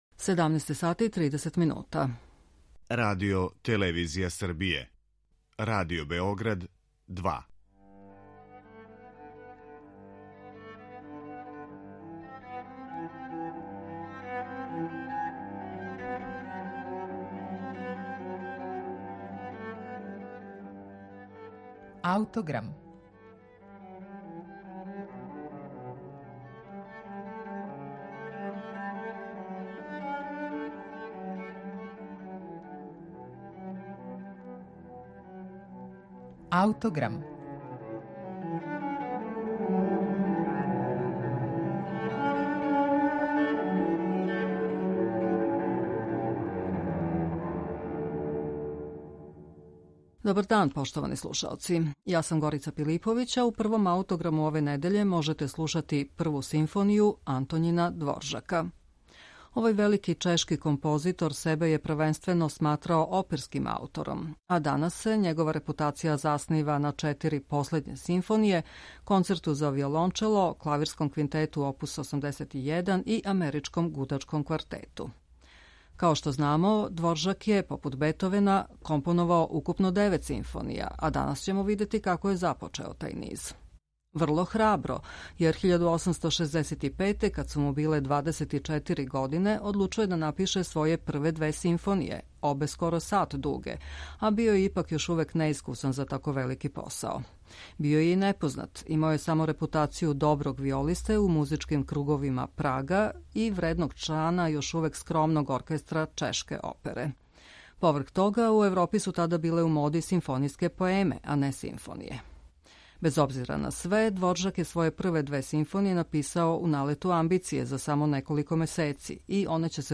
Наводно, неки пасажи у оквирним ставовима звуче као звона.